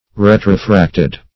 Search Result for " retrofracted" : The Collaborative International Dictionary of English v.0.48: Retrofract \Re"tro*fract\, Retrofracted \Re"tro*fract`ed\, a. [Pref. retro- + L. fractus, p. p. of frangere to break.]